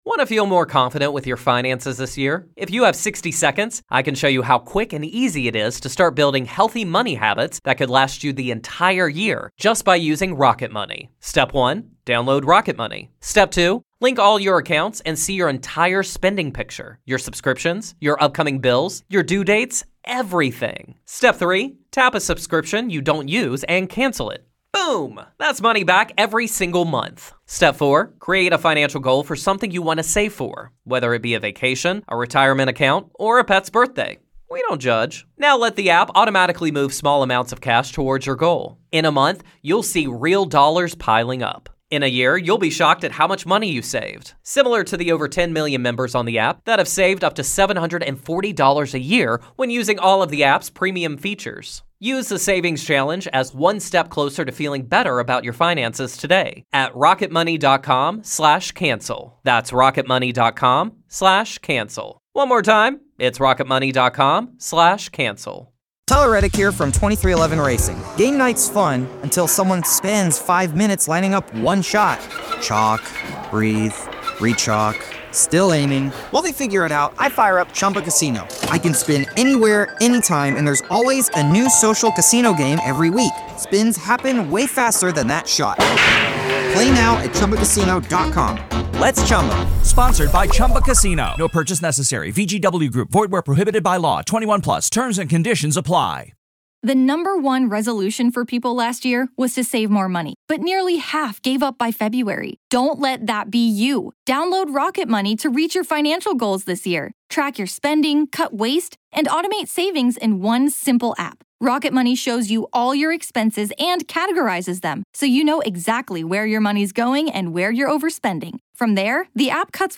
Haunting real ghost stories told by the very people who experienced these very real ghost stories.